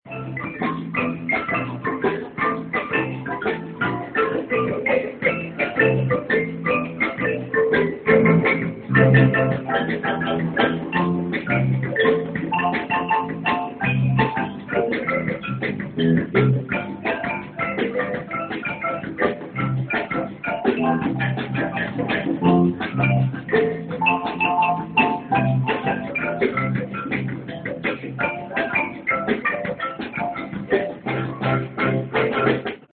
Ifjúsági koncert a Garay téren
Nem volt nálam kedvenc kis fényképezőgépem, így a Nokiával tudtam csak kép/hangrögzíteni.